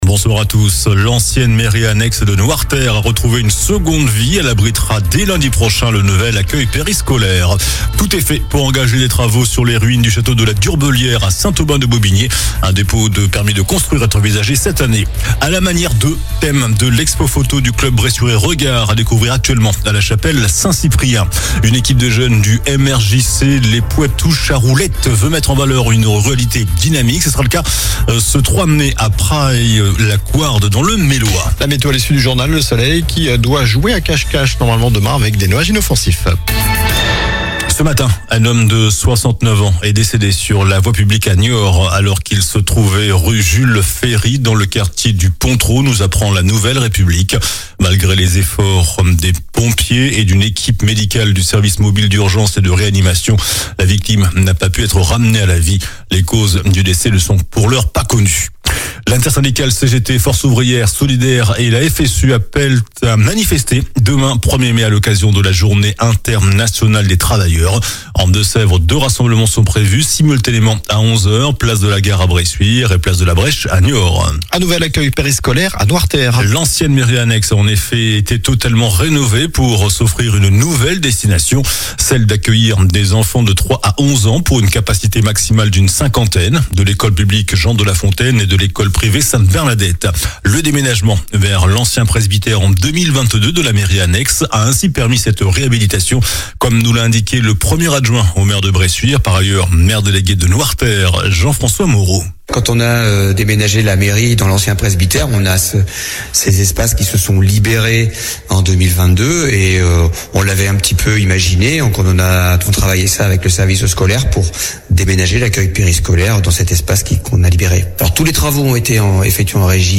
Journal du mercredi 30 avril (soir)